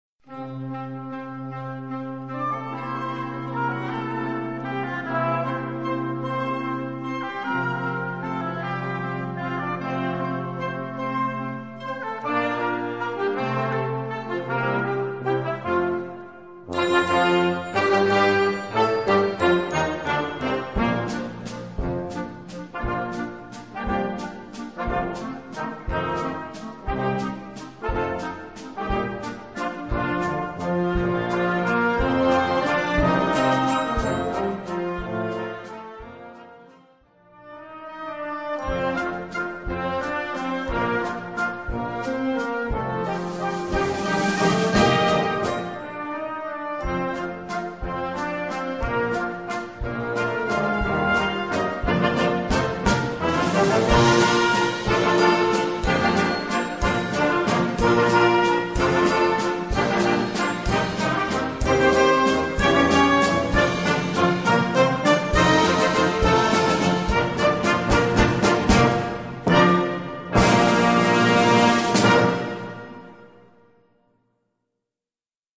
Gattung: Johann-Strauß-Walzer
Schwierigkeit: 1,5
Besetzung: Blasorchester